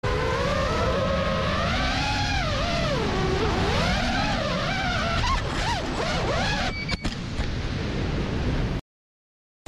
Tiếng máy bay Flycam, drone Rơi, gặp sự cố…
Thể loại: Tiếng đồ công nghệ
Description: Âm thanh sống động với tiếng máy bay flycam, drone rơi, hoặc gặp sự cố, mang đến trải nghiệm chân thực cho video của bạn! Những hiệu ứng âm thanh này, từ tiếng rít tốc độ cao, tiếng động cơ công nghệ, đến tiếng va chạm kịch tính, được thiết kế để lồng tiếng video thêm phần hấp dẫn.
tieng-may-bay-flycam-drone-roi-gap-su-co-www_tiengdong_com.mp3